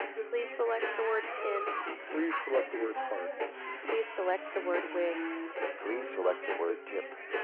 • FM — Software simulation of analog frequency modulation (FM) land-mobile radio (full quieting signal, no interference).
• Club — Speech combined with recorded nightclub noise at 0 dB signal-to-noise ratio (SNR).
• Female speaker — “Please select the word pin.”
• Male speaker — “Please select the word hark.”
fm_club.wav